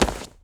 foley_object_grab_pickup_05.wav